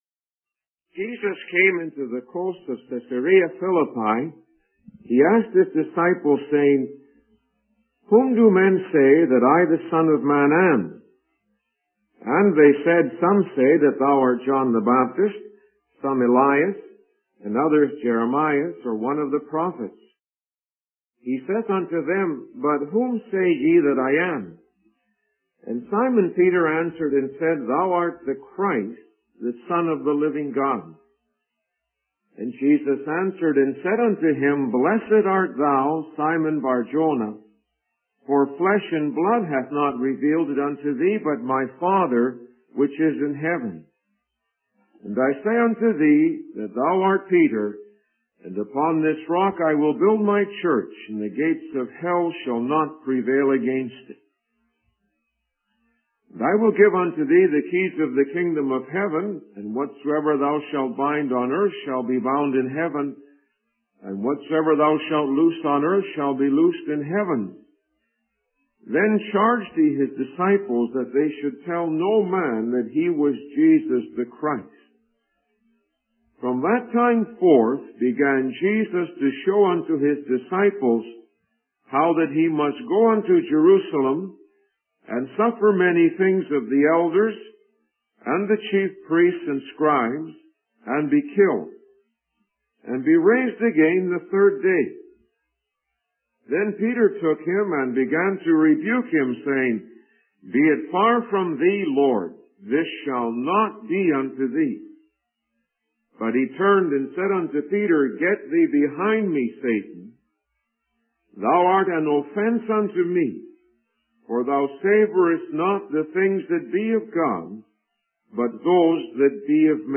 In this sermon, the speaker focuses on the interaction between Jesus and Peter, highlighting Peter's lack of understanding and rebuke of Jesus. The speaker emphasizes the importance of comprehending the message of Jesus and the need to follow in his footsteps. The sermon also discusses the concept of coming glory and the disciples' participation in it.